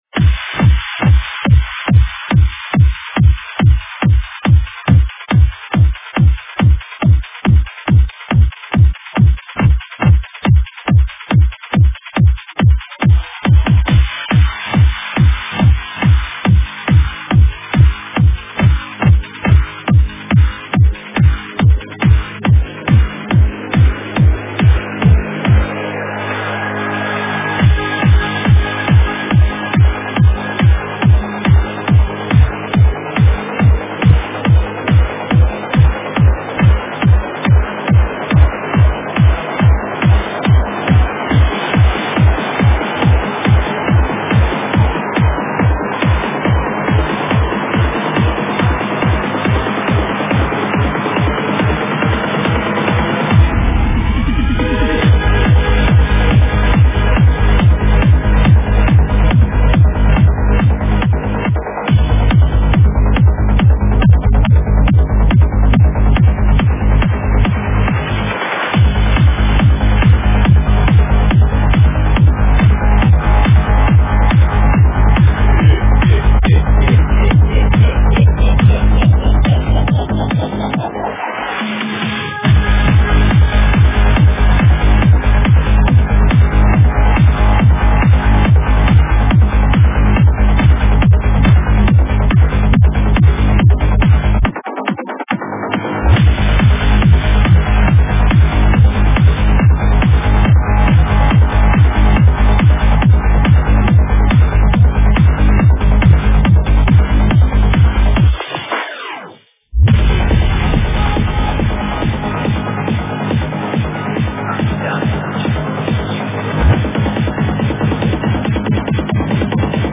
Стиль: Psylifting Trance